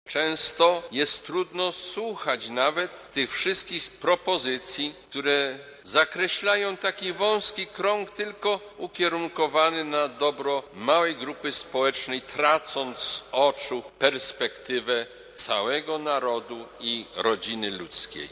Mszą świętą w katedrze w Radomiu rozpoczęły się uroczystości 500-lecia Sejmu Radomskiego i uchwalenia Konstytucji "Nihil novi". W homilii kardynał Józef Glemp, podkreślił, że prawa i ustawy mają służyć wspólnemu dobru, ochronie każdej osoby i całego społeczeństwa.